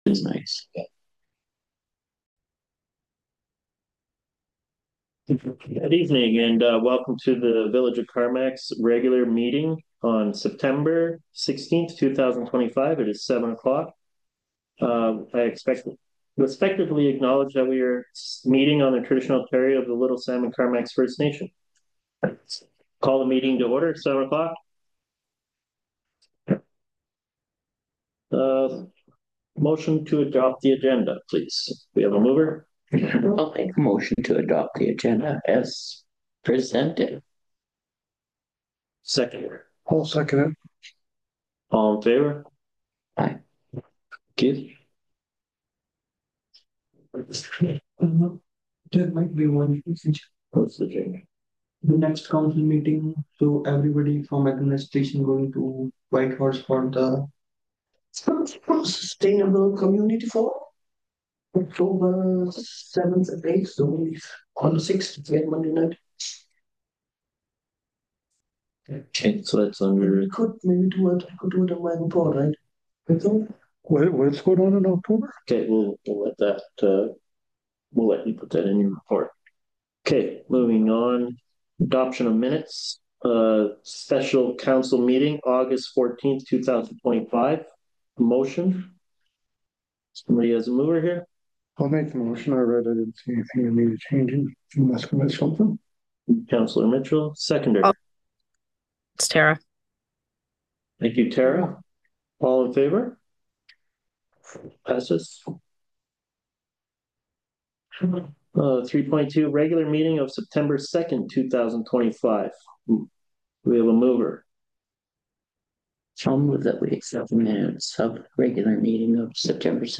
25-18 Council Meeting